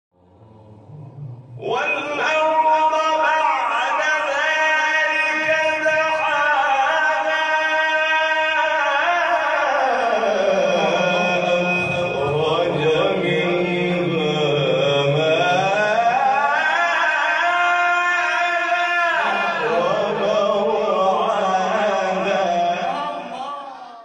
گروه شبکه اجتماعی: نغمات صوتی از تلاوت قاریان ممتاز و بین‌المللی کشور را می‌شنوید.